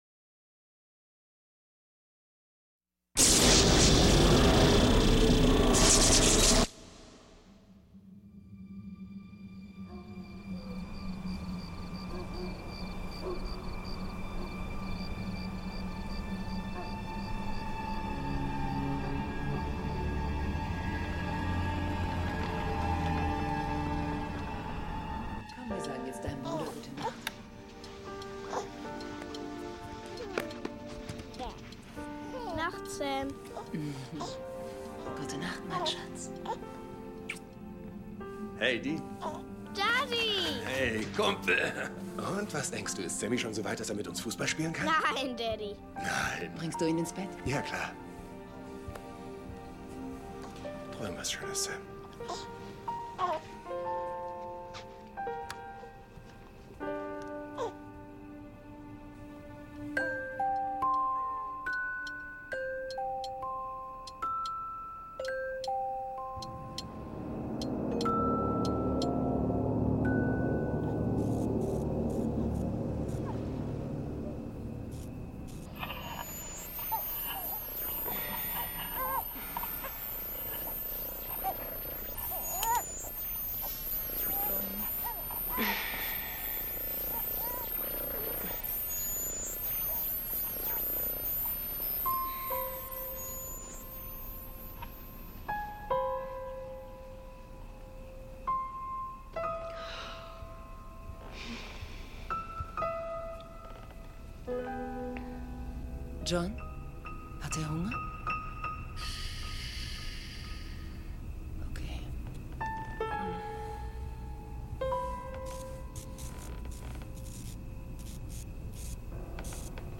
S01E01: Die Frau in Weiß (Pilot) - Supernatural Hörspiel Podcast